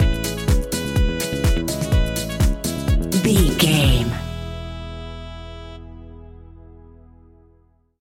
Ionian/Major
uplifting
energetic
bouncy
bass guitar
saxophone
electric piano
drum machine
synth
groovy